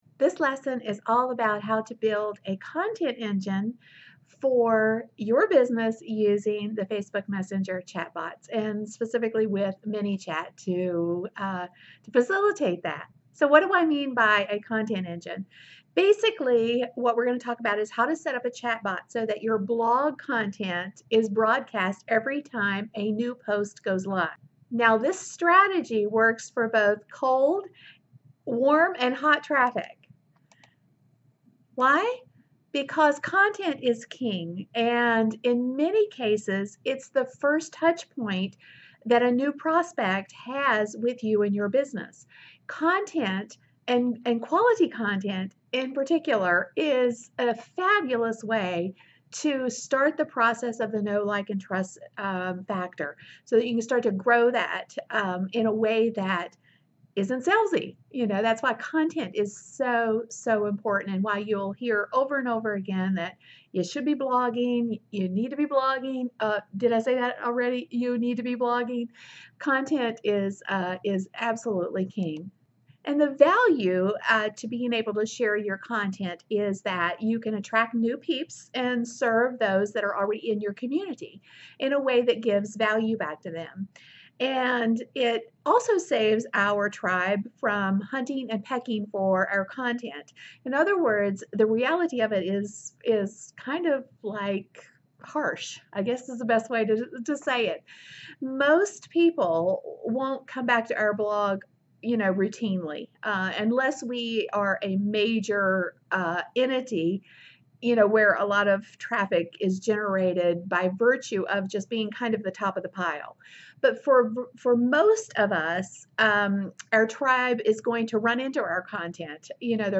In this lesson, you'll learn how to build a content distribution engine using ManyChat.